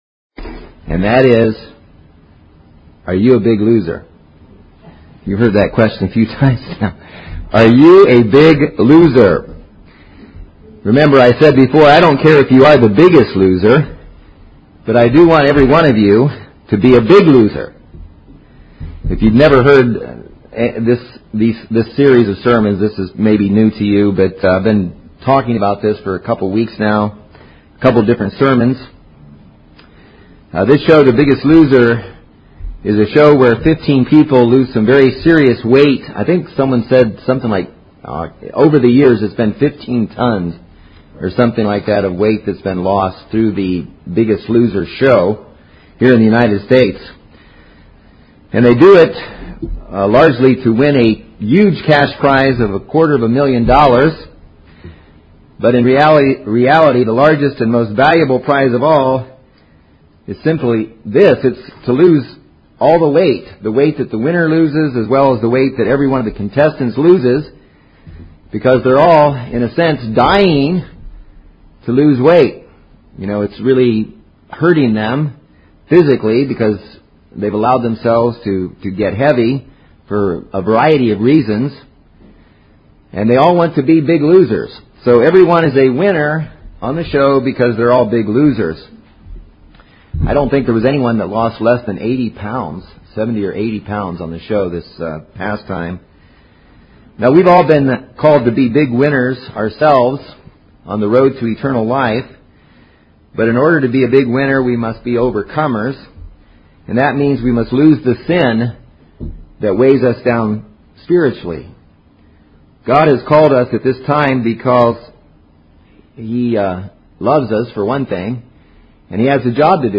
In this series of sermons you will find seven steps to becoming spiritually lean and fit.